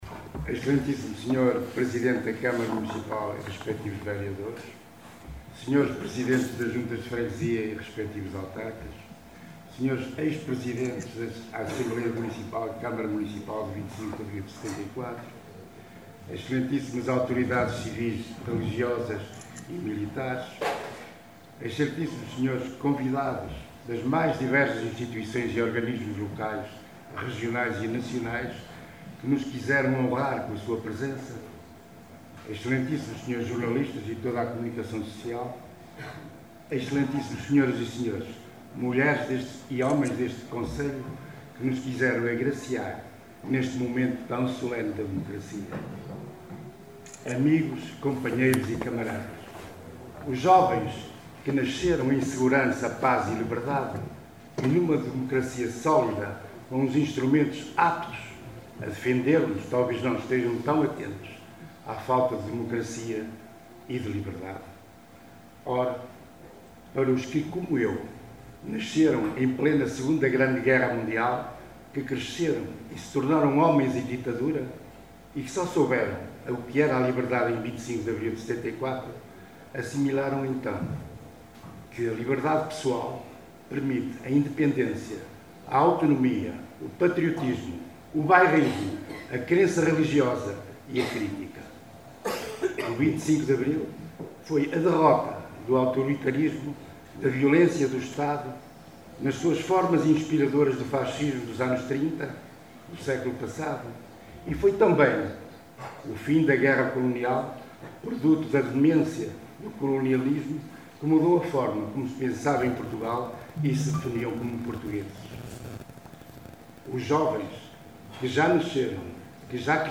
Luis Mourao Discurso
Discurso do ex-presidente da Assembleia Municipal de Caminha, Luís Mourão, em representação da bancada do PS, na sessão solene da Assembleia Municipal comemorativa dos 50 anos da revolução de Abril que decorreu ontem no Teatro Valadares em Caminha.